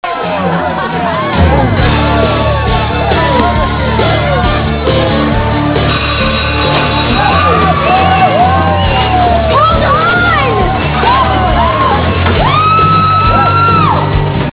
Comment: country western